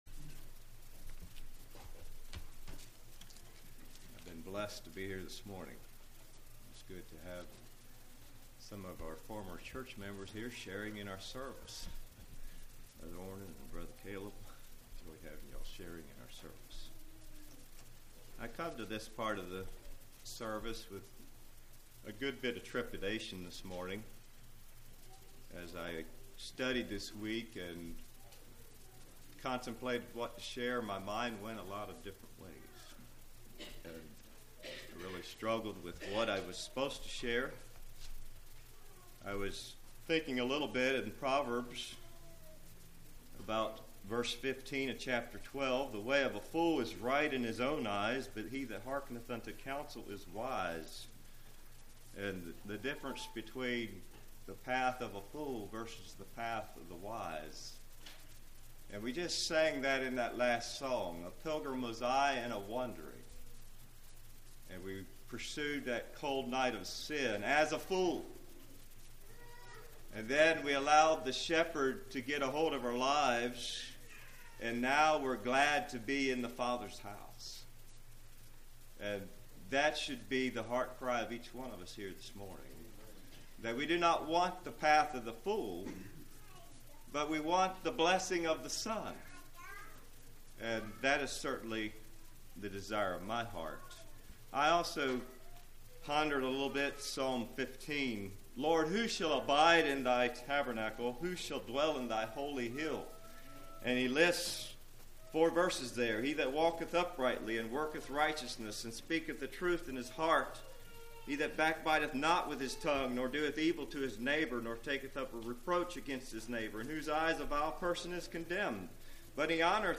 Website of Pilgrim Conference | Christian Sermons, Anabaptist e-Literature, Bible Study Booklets | Pilgrim Ministry
Congregation: Pensacola